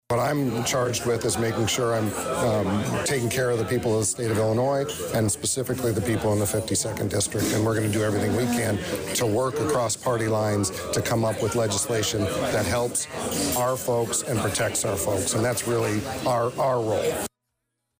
Catching up with Senator Faraci again Thursday at the Gilbert Street Cafe; he stated that no matter what is happening with the presidential race, his focus is first and foremost on local concerns.